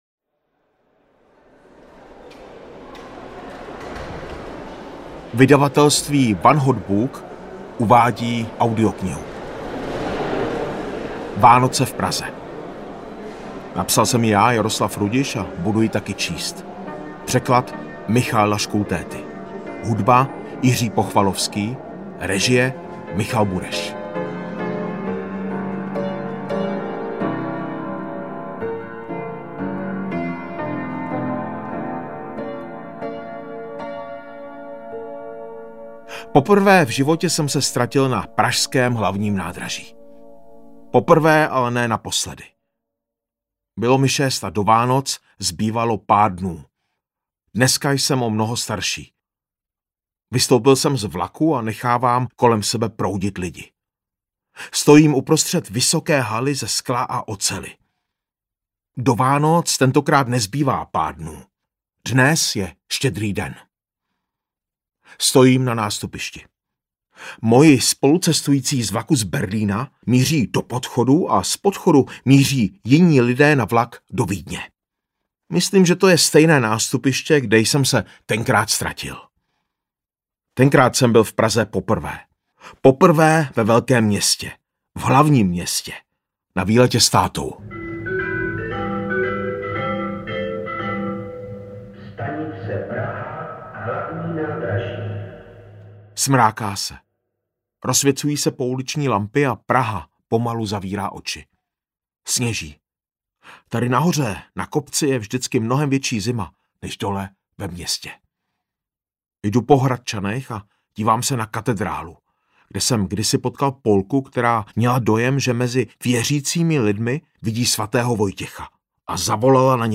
Ukázka z knihy
• InterpretJaroslav Rudiš